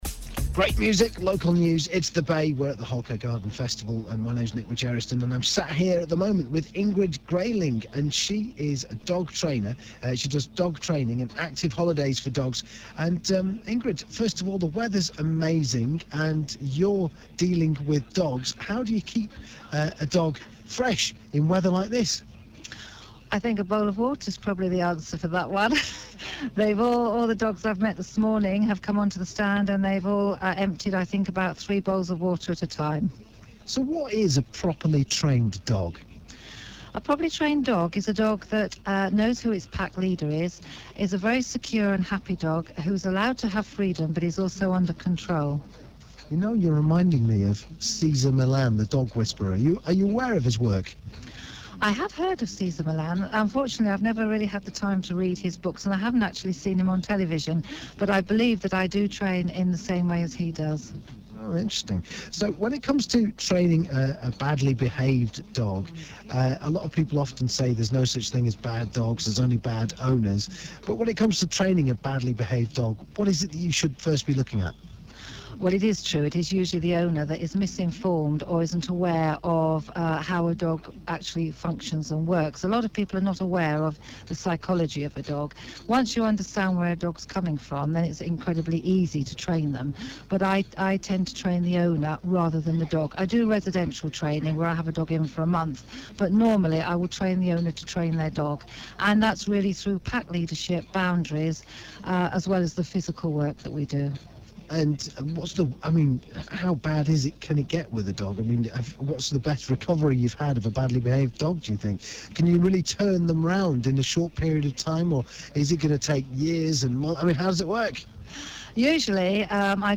It was fun talking on the radio, although a little distracting as we were in an open tent with lots of people looking on….hopefully you’ll like the interview, you can hear it